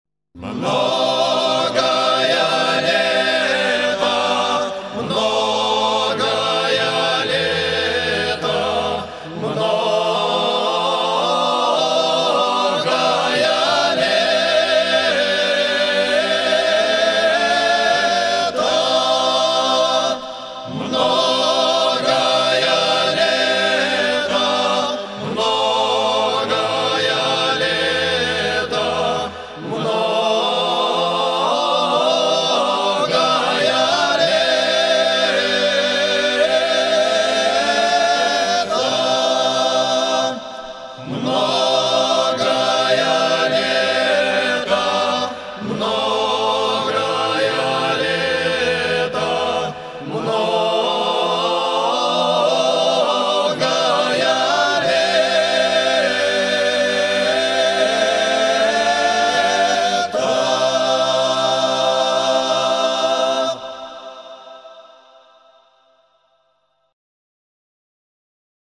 Mnogaya_leta_duhovnoe_pesnopenie_-.mp3